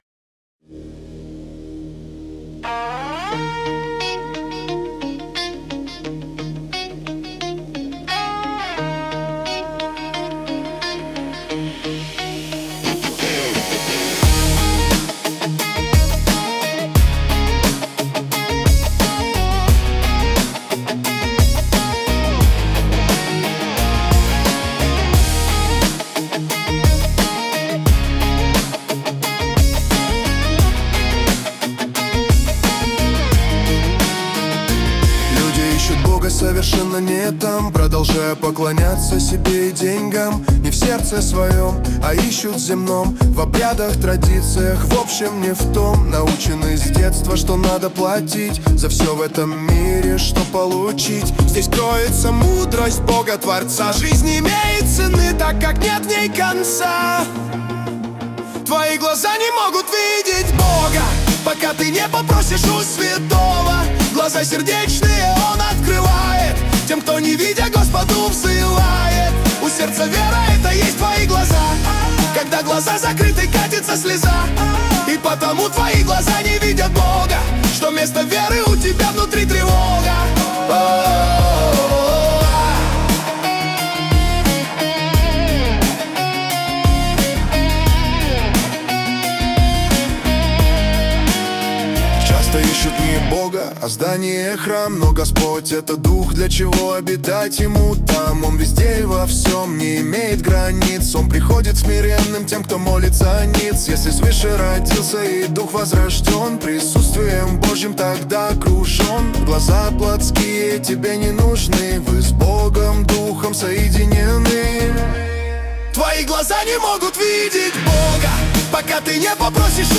песня ai
196 просмотров 720 прослушиваний 109 скачиваний BPM: 88